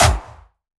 Jumpstyle Kick 4
14 AttackNoize.wav